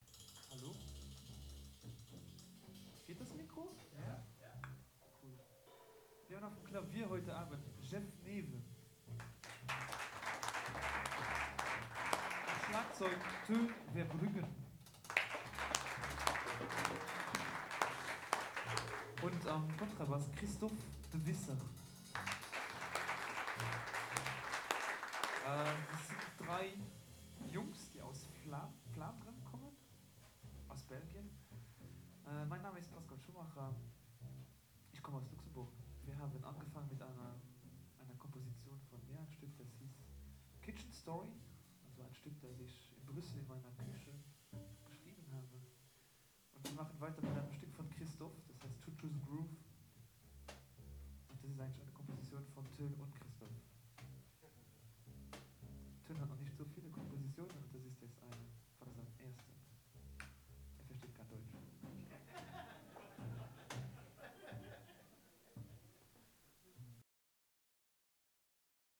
103 - Ansage Pascal Schumacher.mp3